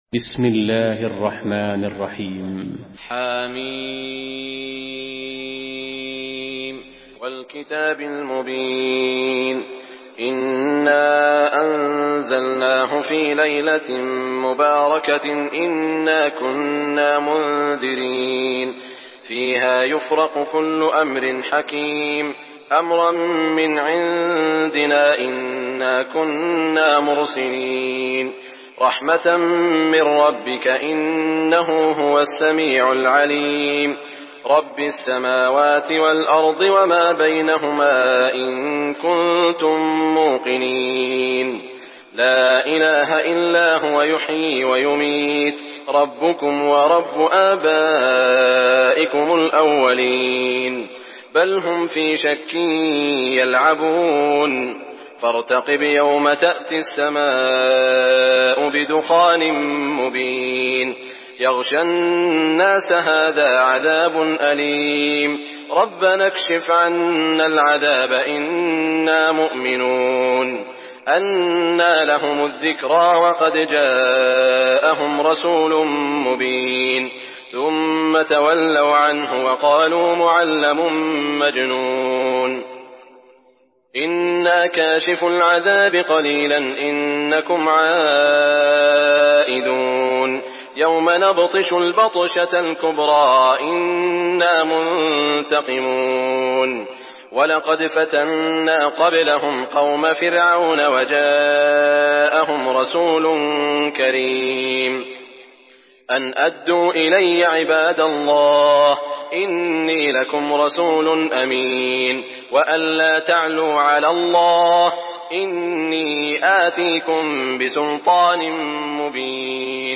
سورة الدخان MP3 بصوت سعود الشريم برواية حفص
مرتل